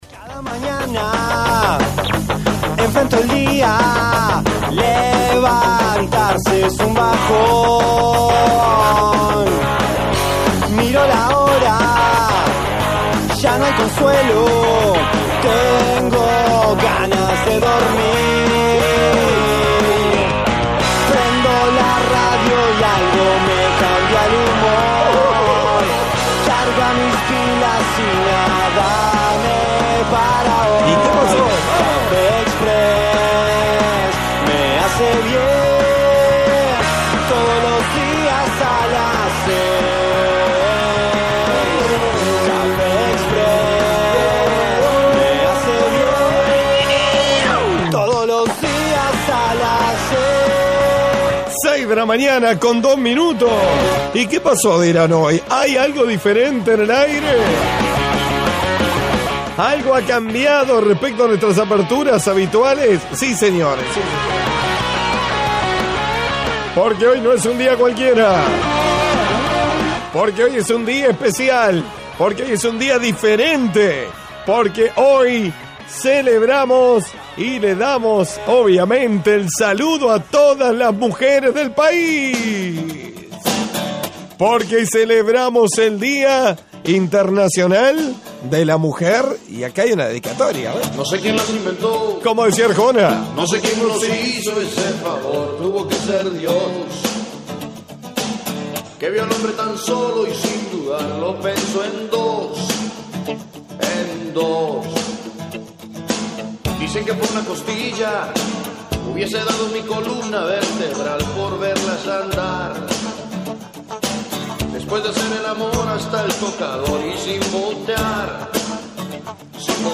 Por eso hoy ESPECIAL DE CAFÉ EXPRESS 10 AÑOS con música interpretada sólo por mujeres.